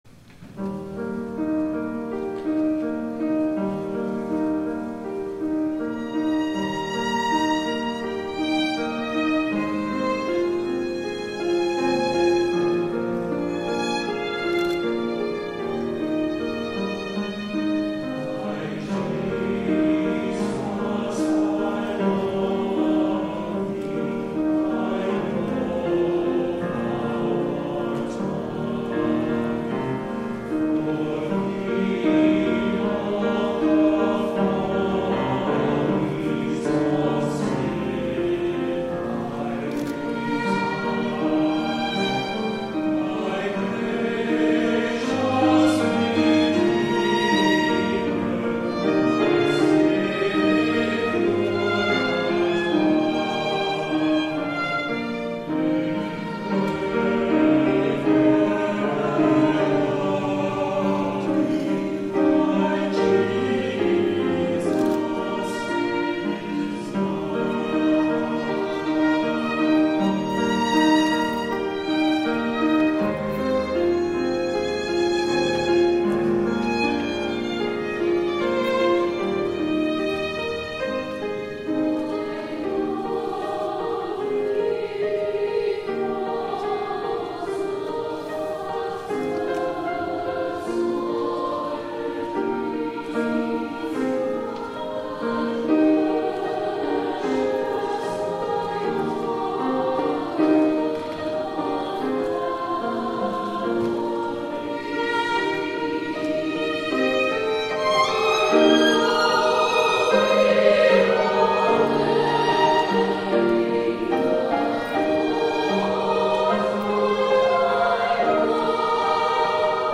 11 A.M. WORSHIP
THE ANTHEM